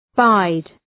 Shkrimi fonetik {baıd}